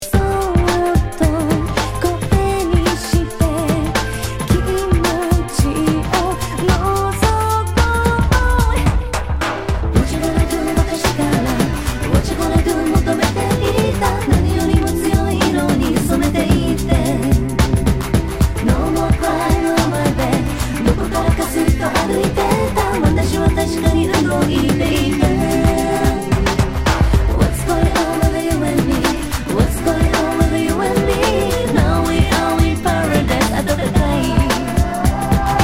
Category       レコード / vinyl 12inch
Tag       Japan R&B